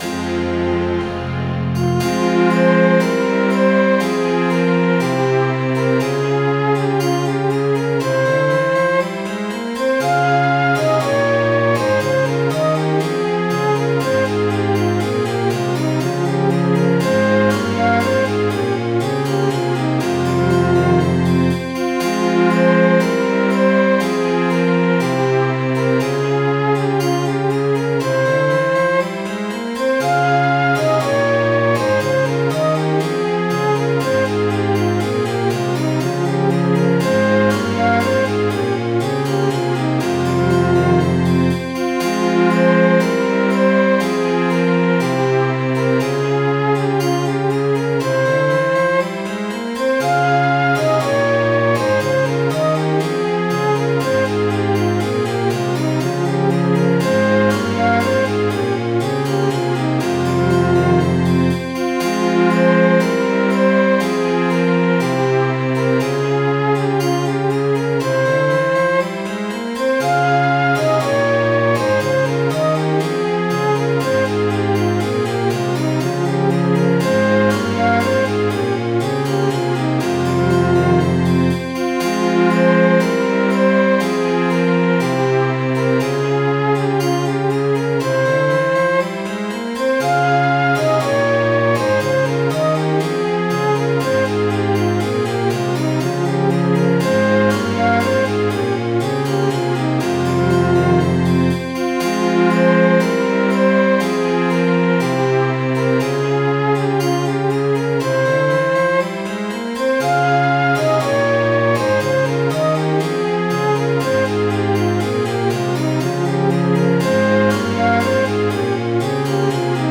Midi File, Lyrics and Information to The Cheshire Man